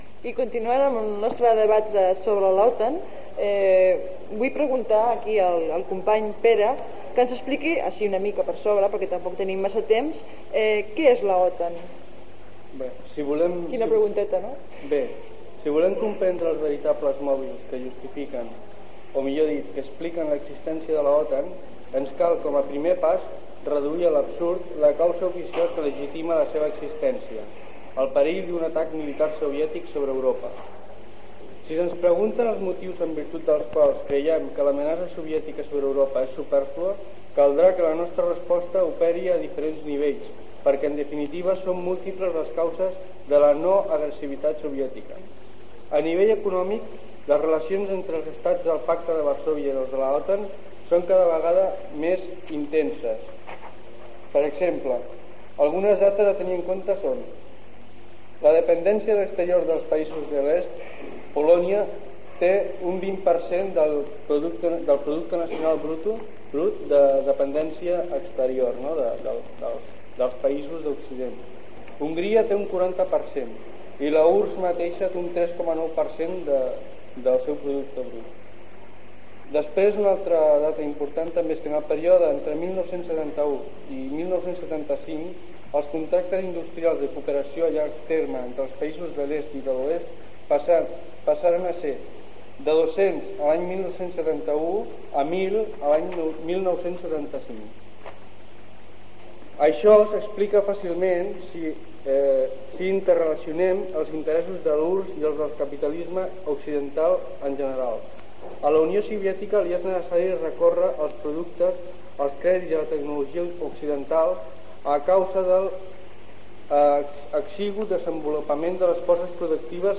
Debat sobre l'OTAN
Banda FM